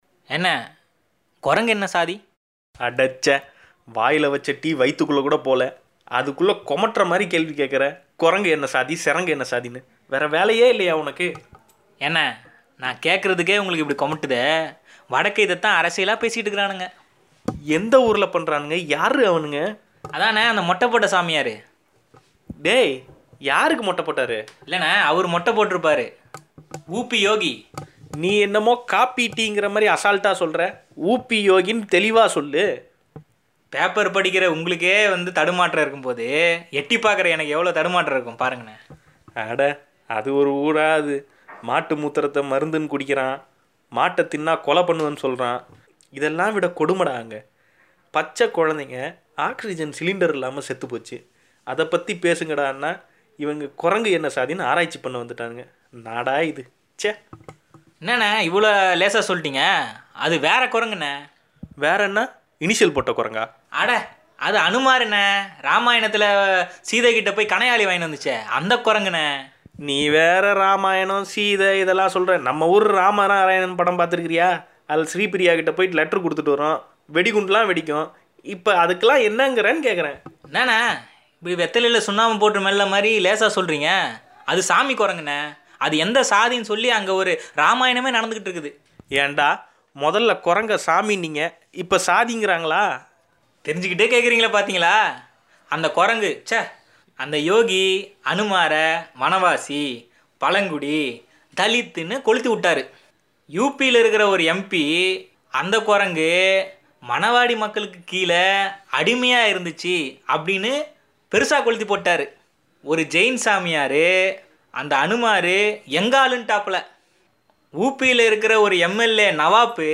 அதோடு சொல்லிட்டுப் போங்கண்ணே ! என்ற நகைச்சுவைத் தொடரையும் உரையாடல் பாணியில் கேட்பொலியாக வழங்கியுள்ளோம்.